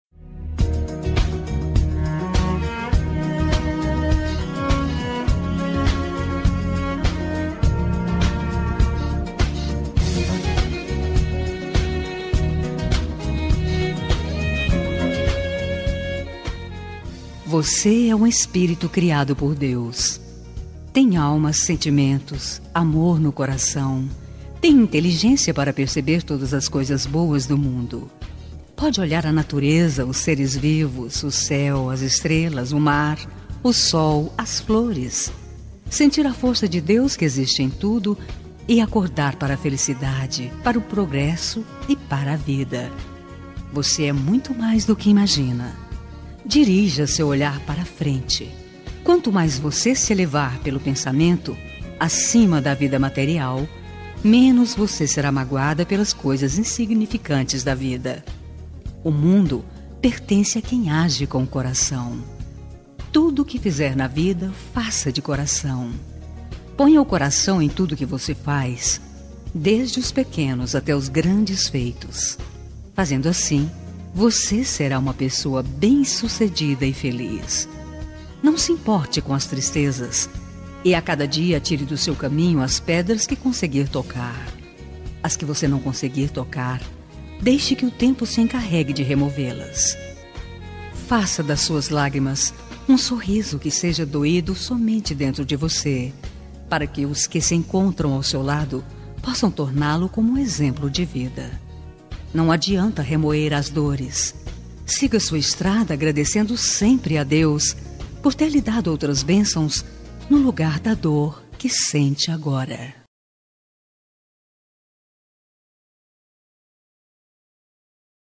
Telemensagem de Otimismo – Voz Feminina – Cód: 7538 – Linda